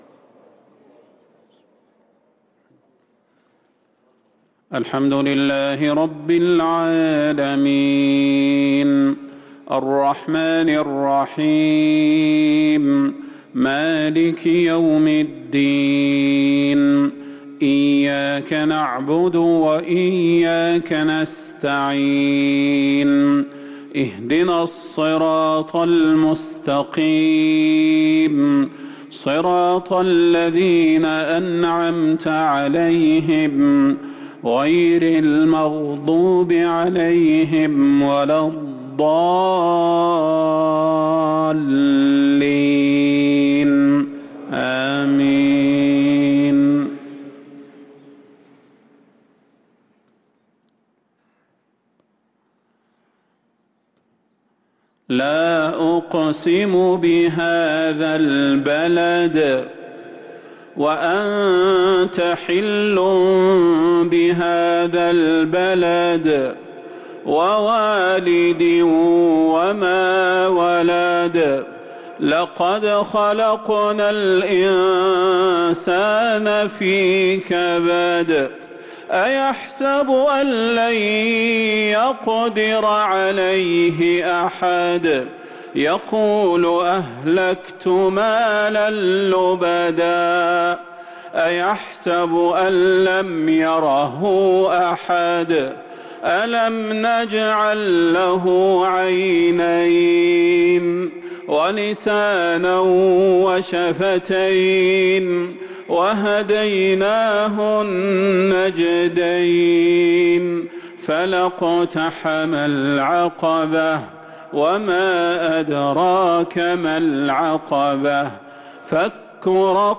صلاة المغرب للقارئ صلاح البدير 25 ذو القعدة 1444 هـ
تِلَاوَات الْحَرَمَيْن .